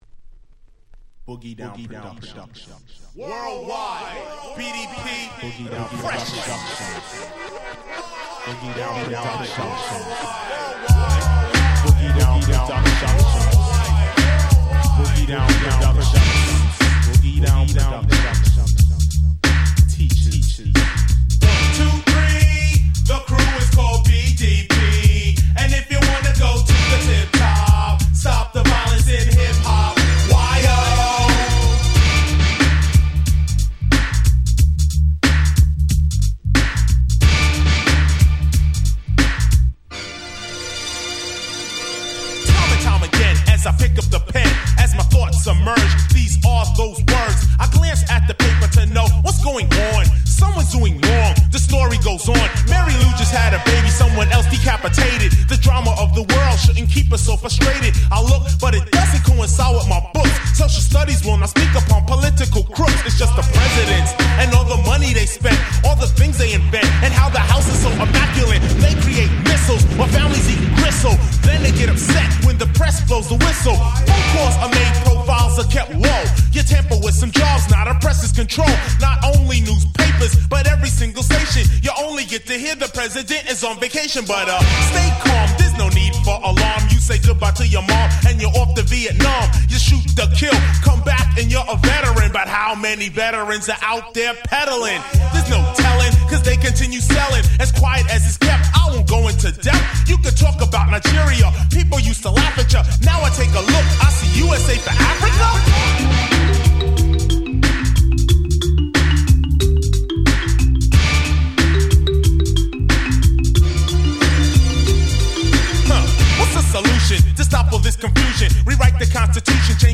88' Hip Hop Super Classics !!